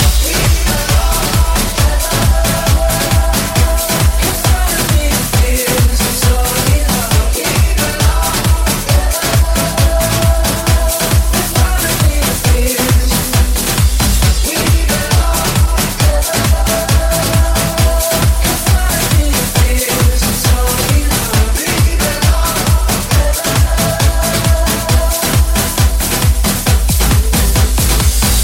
Genere: tribal,anthem,circuit,remix,hit